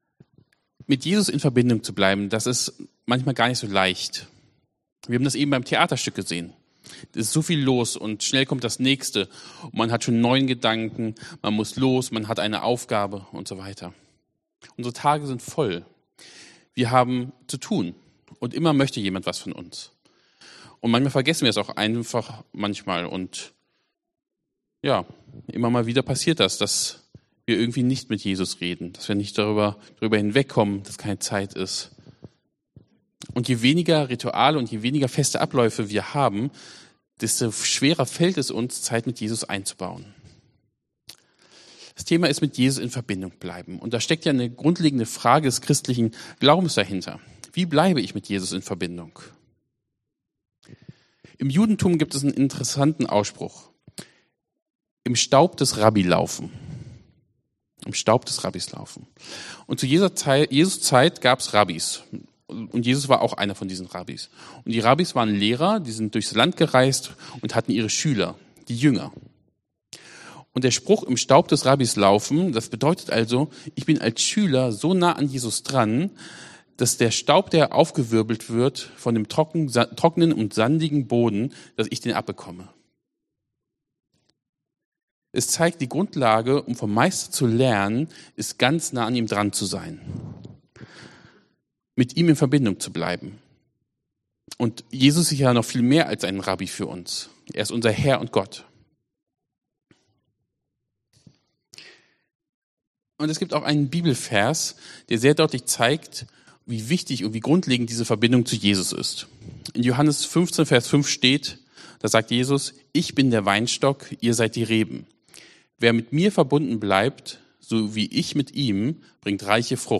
wenn wir widerhallen Dienstart: Predigt « Nehemia